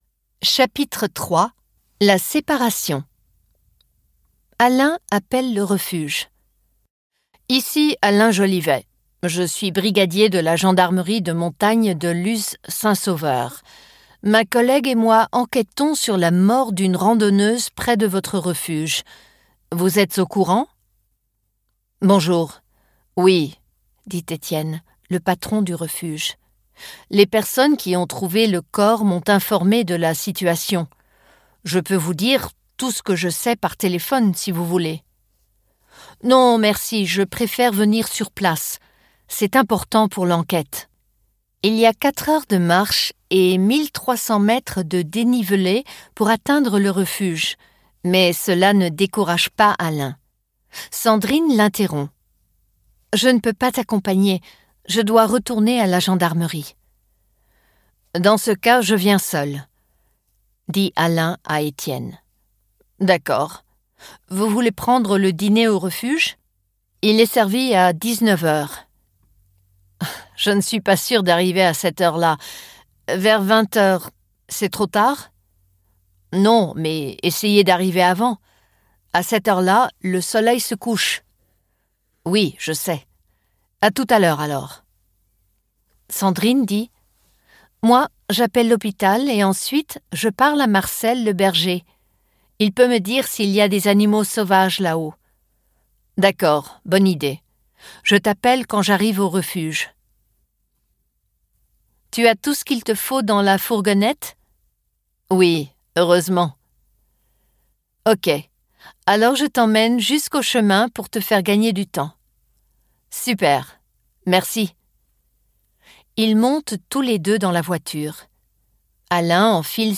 Compact Lernkrimi Hörbücher sind die ideale Kombination aus spannungsgeladenem Krimi-Hörgenuss und effektivem Sprachtraining. Die Kriminalgeschichte wird von Muttersprachlern gelesen und eignet sich hervorragend, um das Hörverständnis gezielt zu verbessern.